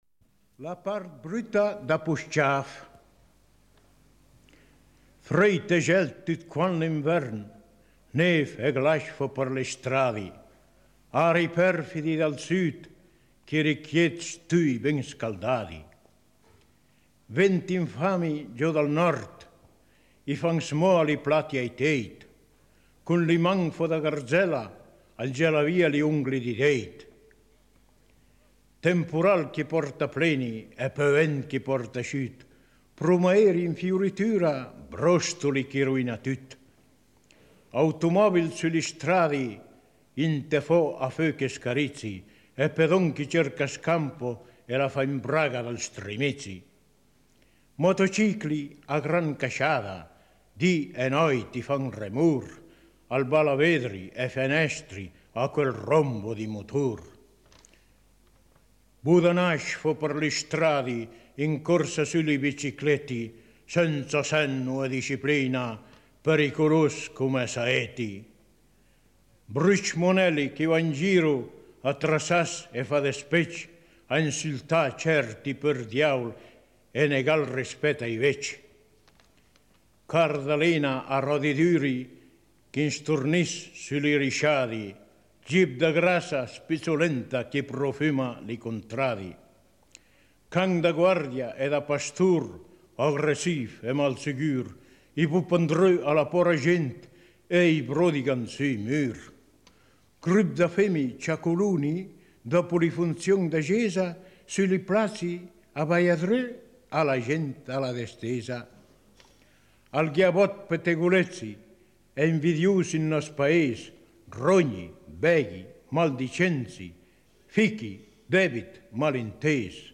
Il poeta dialettale poschiavino